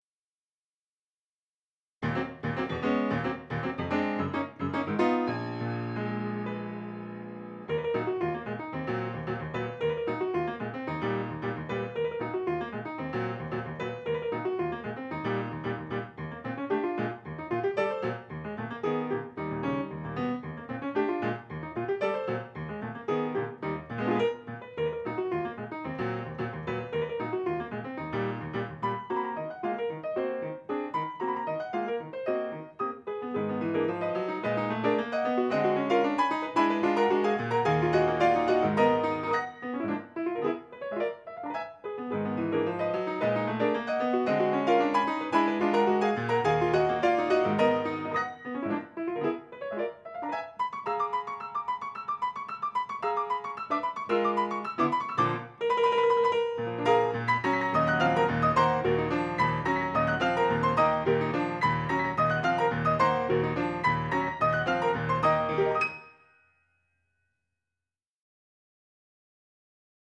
Complete piano music